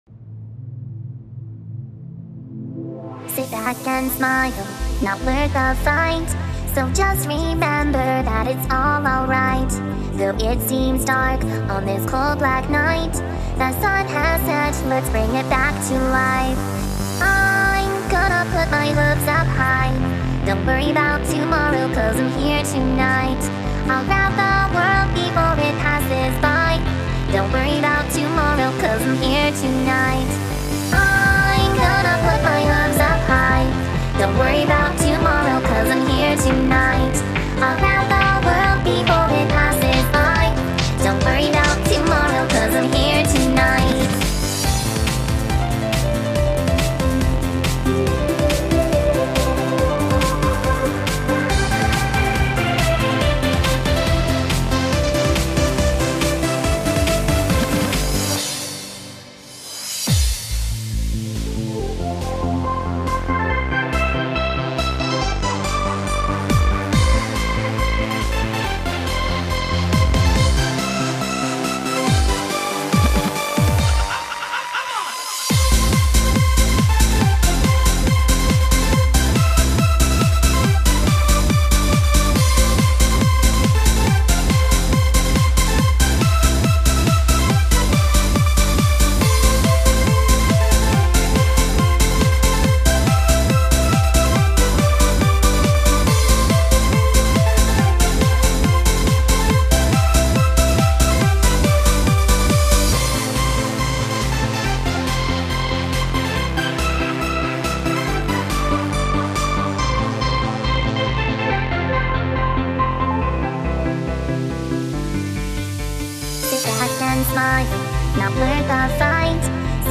It's actually Hands-Up